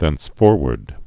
(thĕns-fôrwərd, thĕns-) also thence·for·wards (-wərdz)